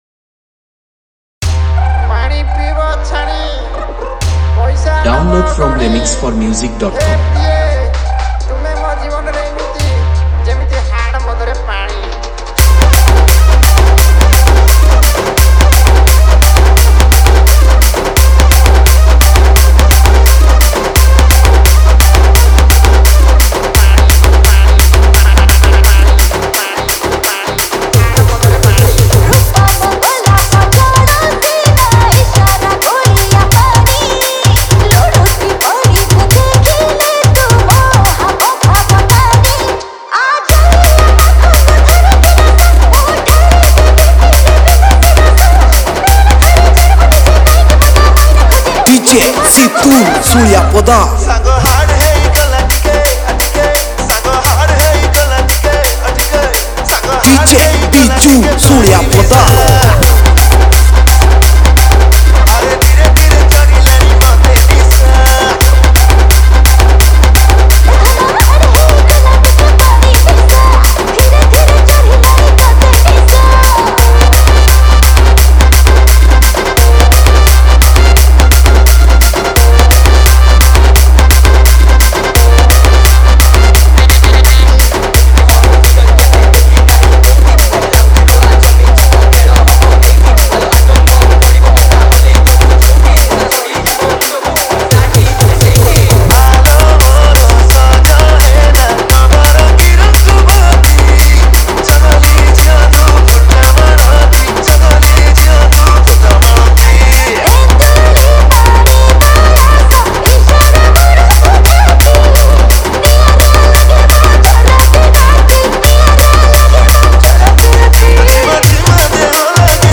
Category : Odia Remix Song